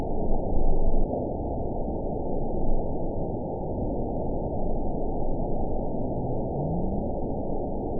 event 920311 date 03/14/24 time 22:37:39 GMT (1 year, 3 months ago) score 9.23 location TSS-AB05 detected by nrw target species NRW annotations +NRW Spectrogram: Frequency (kHz) vs. Time (s) audio not available .wav